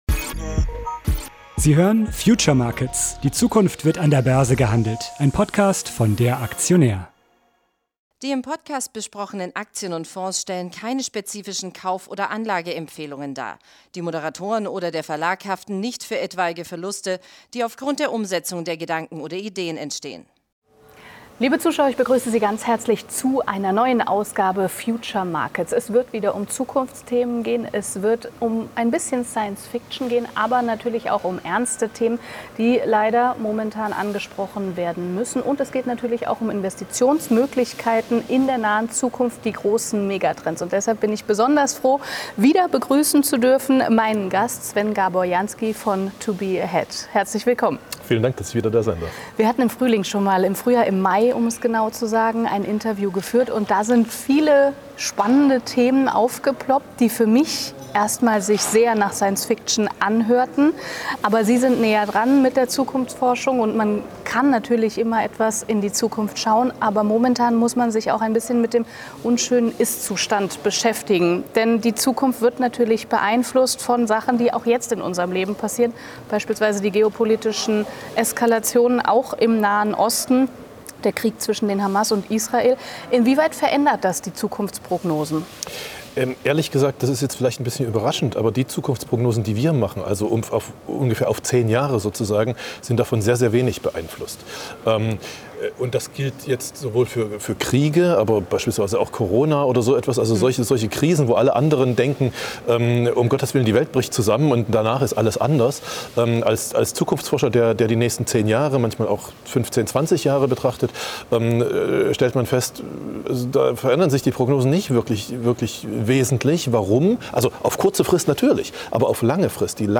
Das Gespräch wurde am 19.06.2024 an der Frankfurter Börse aufgezeichnet.